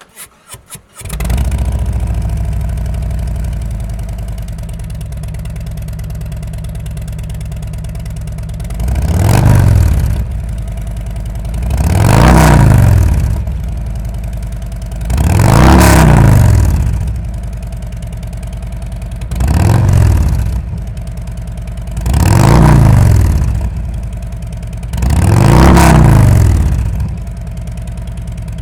DBX40_Rev.wav